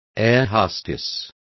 Also find out how azafata is pronounced correctly.